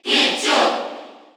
Category: Crowd cheers (SSBU) You cannot overwrite this file.
Pichu_Cheer_Korean_SSBU.ogg